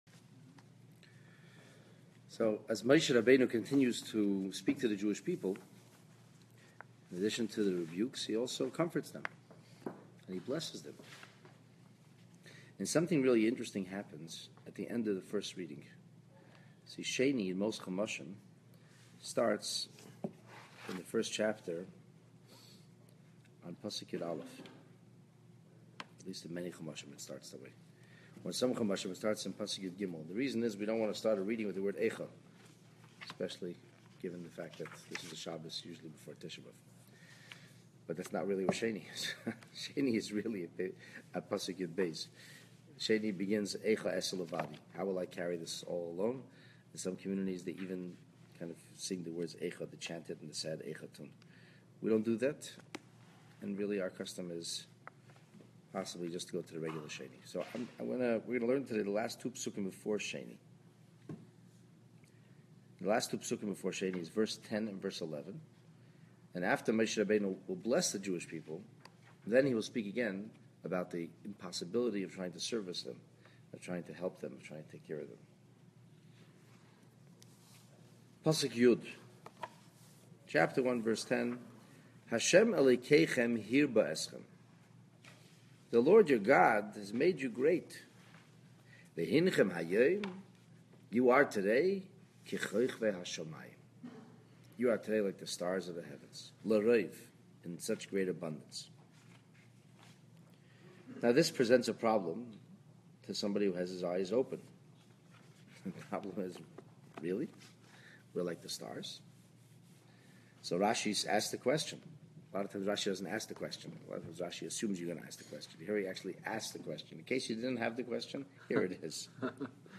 Parshat Devarim, 1st Portion (Deuteronomy 1:1-1:11) Examining the weekly Torah reading through the lens of contemporary commentary, showing how topical and relevant the parshas’ ideas really are. Both mystical and pragmatic, this lesson will elevate your spirit and refine your view of the world around you.